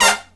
HIGH HIT07-L.wav